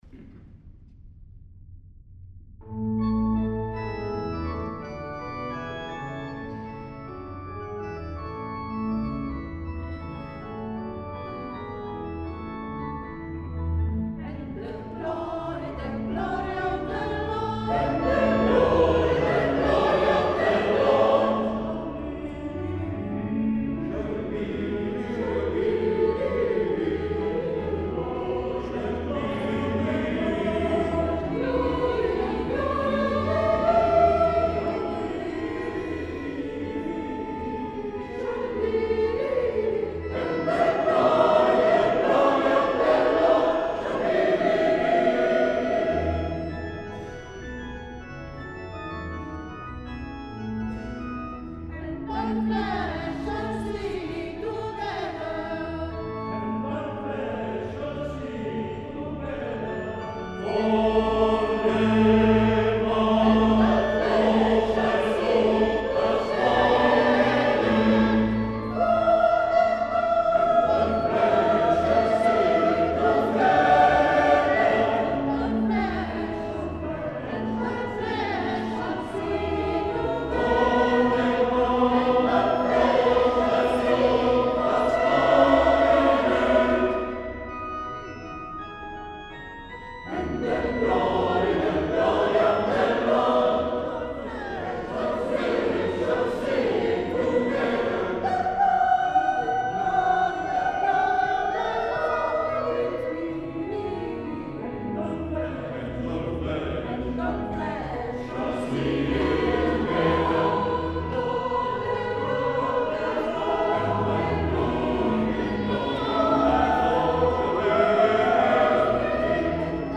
La Corale San Gaudenzio di Gambolo' (Church Choir)2015
Concerti di Natale nella Chiesa Parrocchiale
Registrazione audio MP3 di alcuni brani del concerto